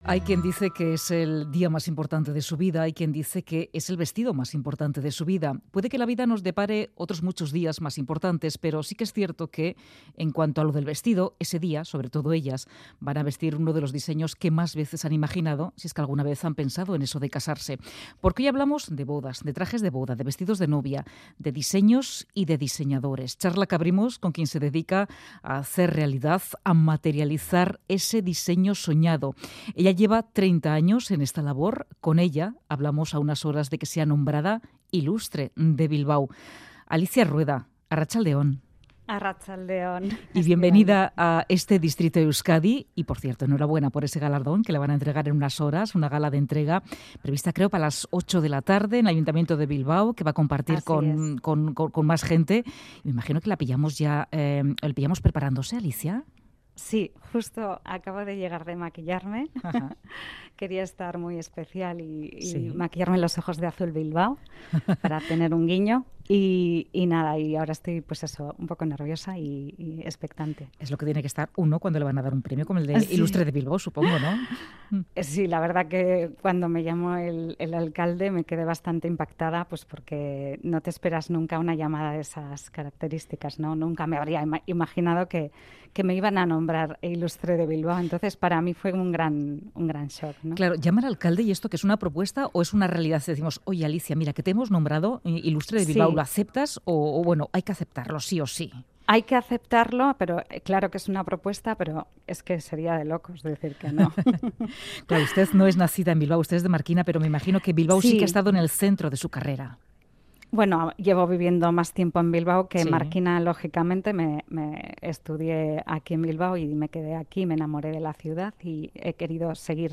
Llegó a Bilbao a estudiar, y la ciudad ha sido testigo desde entonces de su desarrollo tanto profesional como personal. Nos recibe en su atelier.
Radio Euskadi ENTREVISTAS